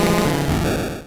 Cri de Magicarpe dans Pokémon Rouge et Bleu.